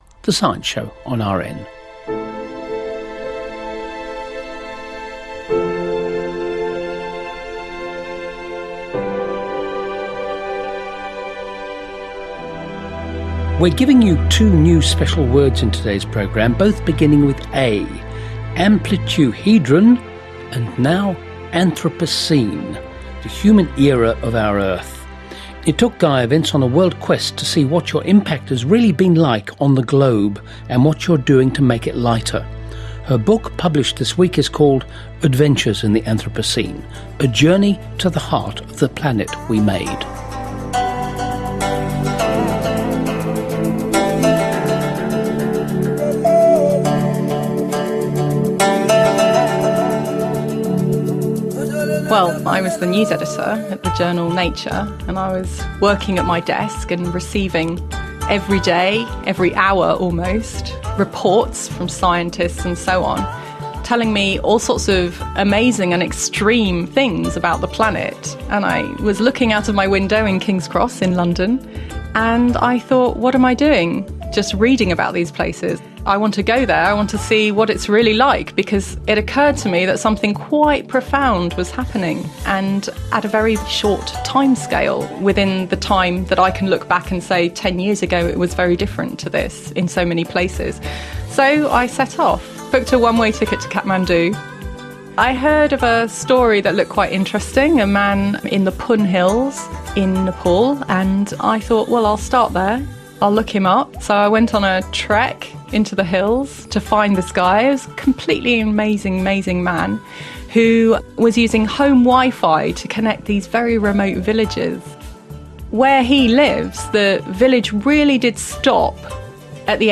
ABC Radio National science star Robyn Williams interviewed me about my book for the Science Show, broadcast today across Australia.